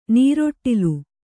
♪ nīroṭṭilu